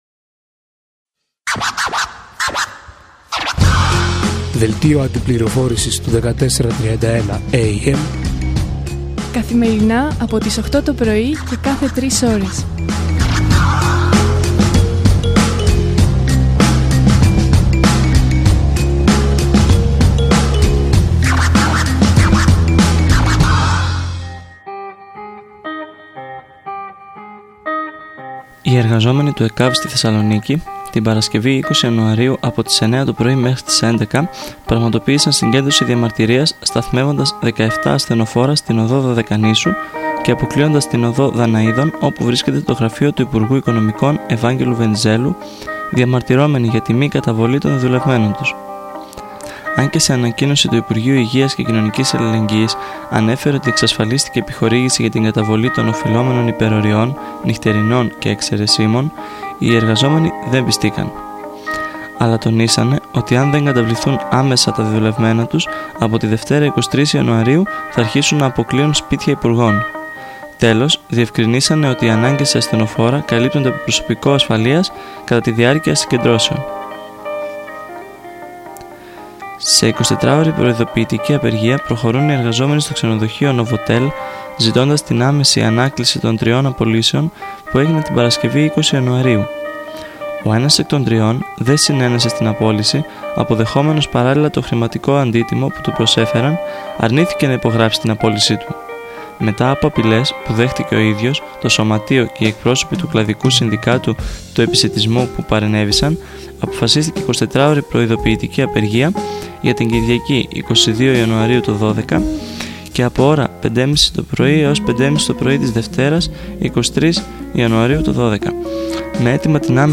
Δελτίο Αντιπληροφόρησης